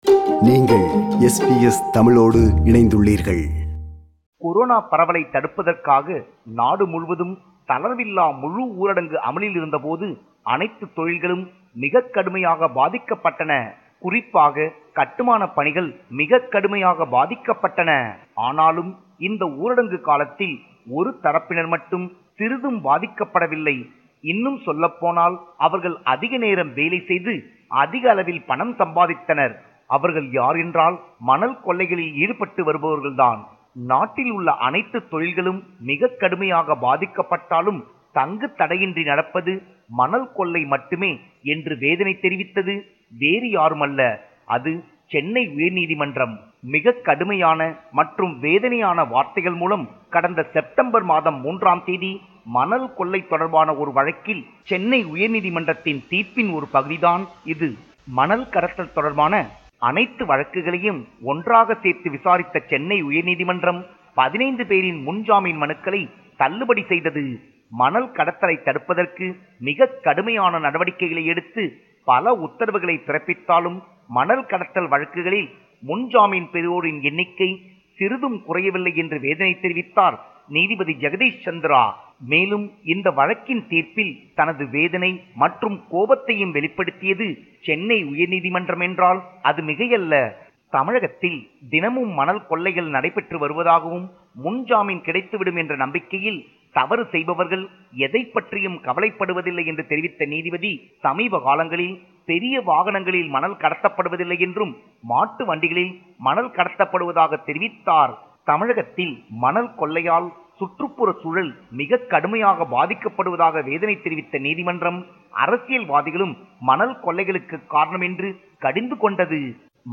தமிழ்நாட்டில் அனைத்து தொழில்களும் பாதிக்கப்பட்டாலும் தடை இன்றி நடப்பது மணல் கொள்ளை மட்டுமே என்று சென்னை உயர் நீதிமன்றம் வேதனை தெரிவித்தது. கடந்த செப்டம்பர் மாதம் 3-ஆம் தேதி மணல் கொள்ளை தொடர்பான ஒரு வழக்கில் உயர்நீதிமன்றம் பரபரப்பான தீர்ப்பைத் தந்தது. இந்த தீர்ப்பின் முக்கியத்துவம், மணல்கொள்ளையின் பின்னணி என்று பல்வேறு அம்சங்களை விளக்கும் விவரணம் இது.